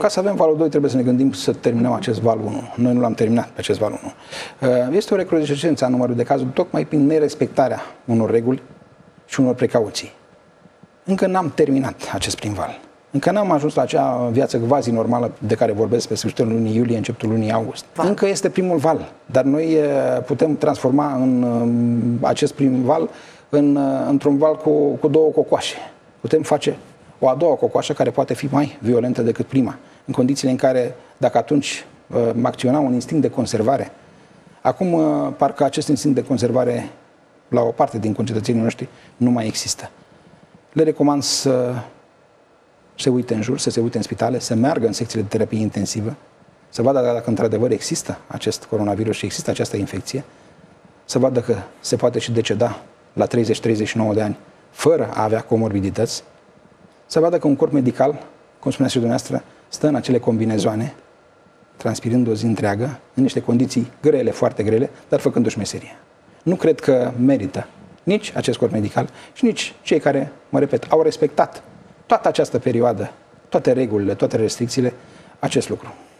Ministrul Sănătății a declarat, luni, că a fost decisă amânarea măsurilor de relaxare care ar fi trebuit să intre în vigoare la 1 iulie.